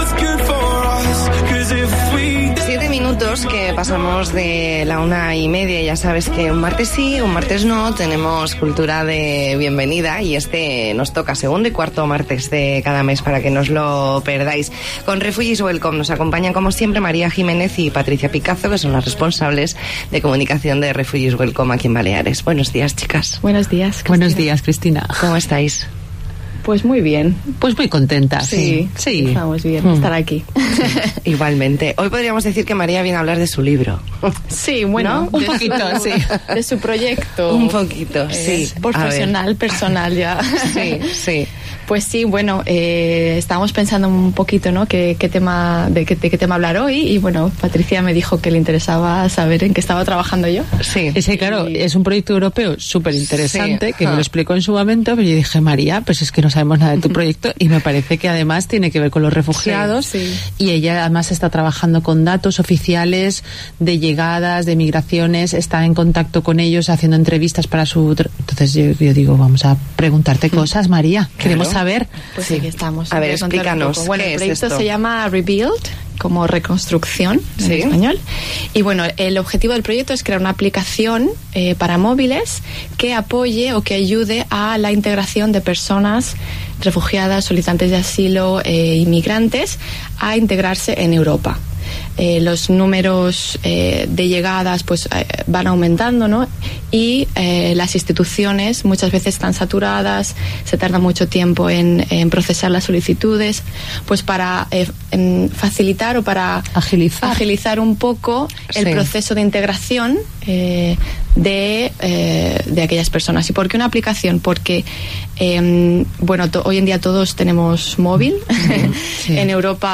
Entrevista en 'La Mañana en COPE Más Mallorca', martes 10 de diciembre de 2019.